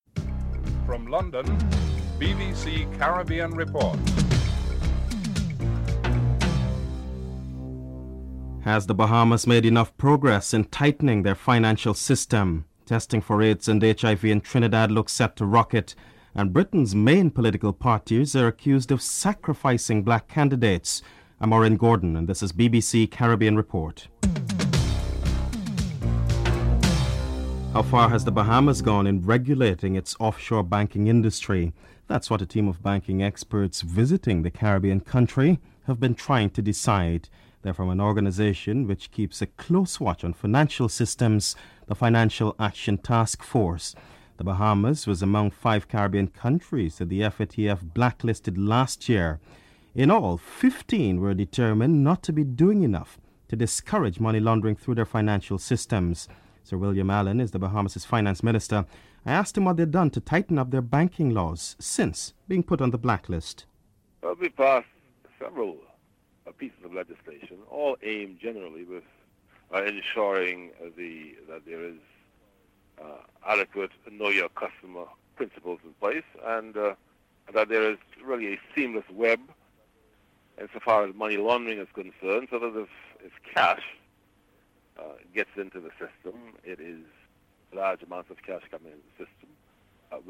1. Headlines (00:00-00:26)
2. Has the Bahamas made enough progress in tightening their financial system? Financial Minister Sir William Allen is interviewed (00:27-03:50)